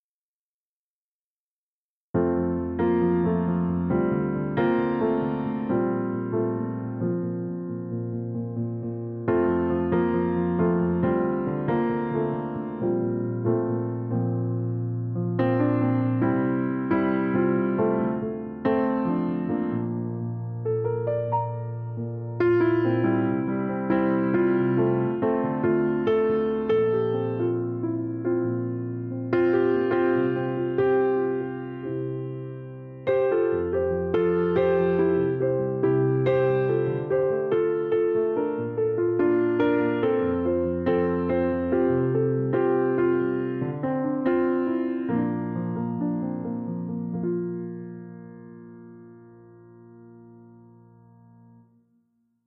Hintergrundpianistin
Hintergrundmusik bedeutet für mich, einfühlsam Klavier zu spielen und intuitiv auf den jeweiligen Anlass einzugehen.
Einige Hörbeispiele für solche aus dem Moment heraus entstandenen Improvisationen: